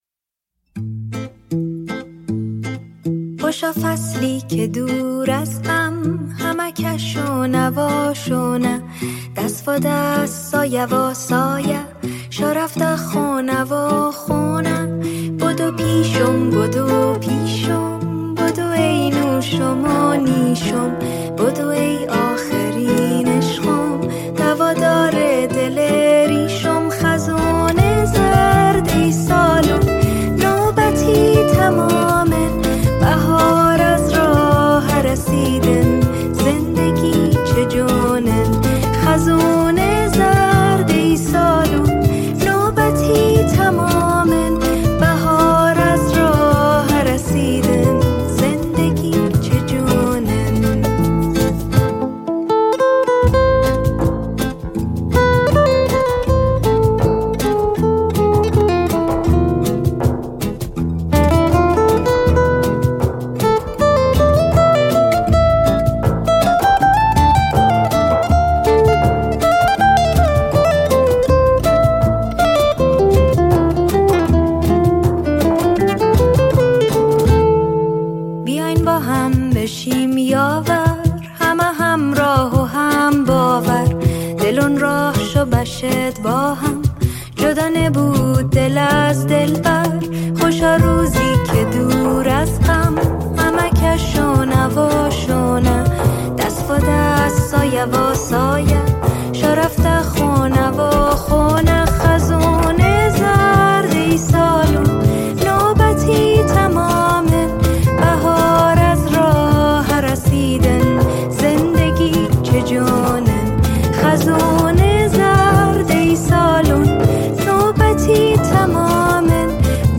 موسیقی فولکلور ایرانی